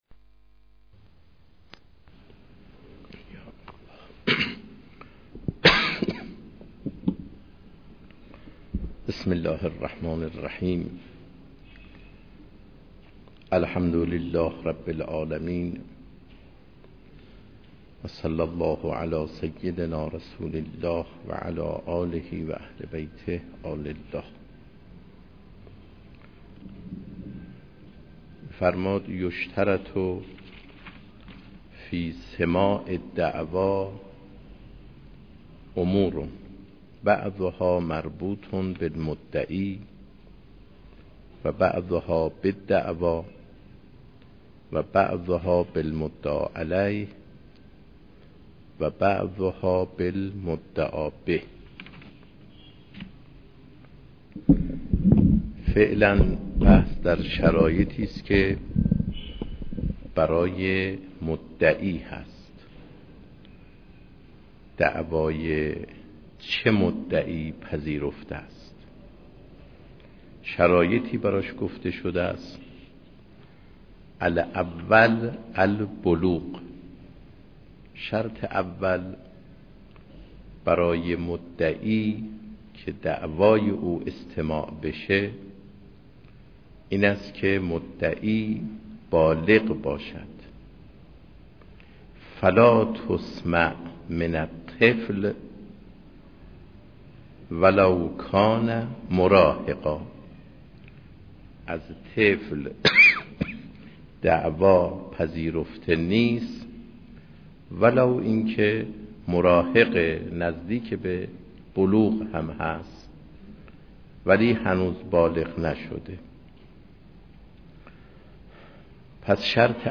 آيت الله مقتدايي - قضاء | مرجع دانلود دروس صوتی حوزه علمیه دفتر تبلیغات اسلامی قم- بیان